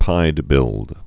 (pīdbĭld)